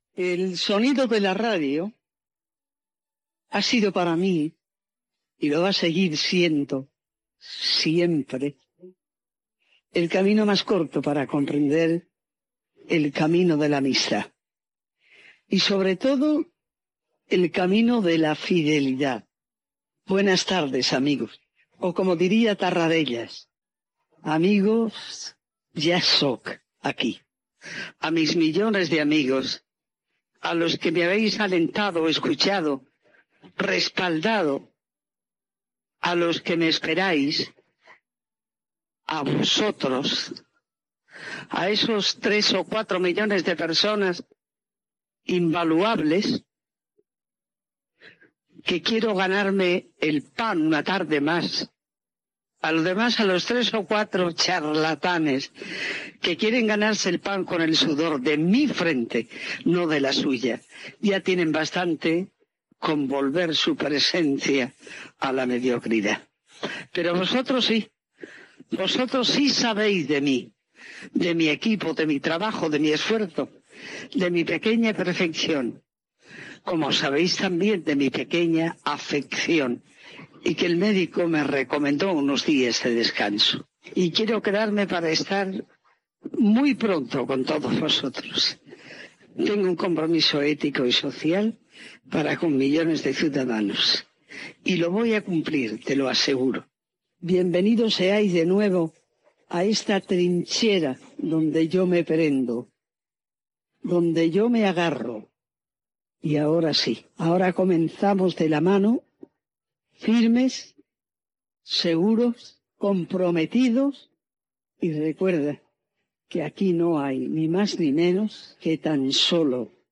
Paraules de la locutora deu dies abans de la seva mort, enregistrades a casa seva
Entreteniment